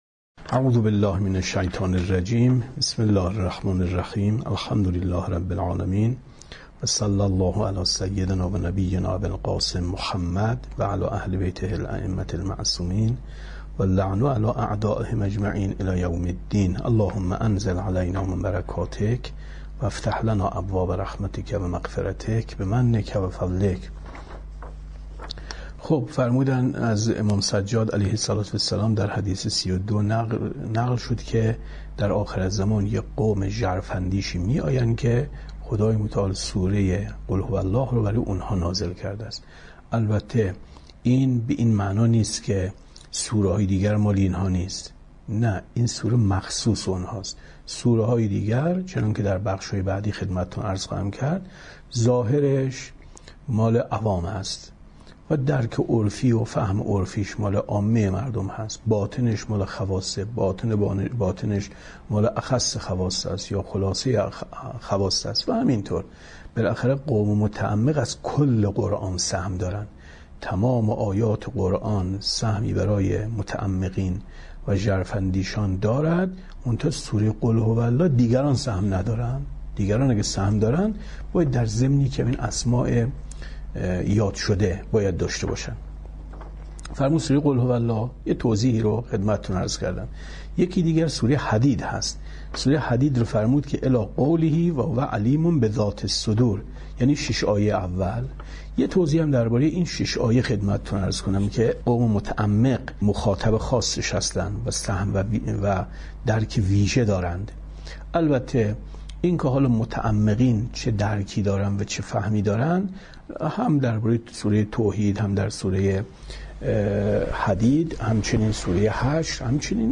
کتاب توحید ـ درس 43 ـ 21/ 10/ 95